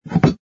sfx_put_down_bottle01.wav